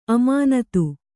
♪ amānatu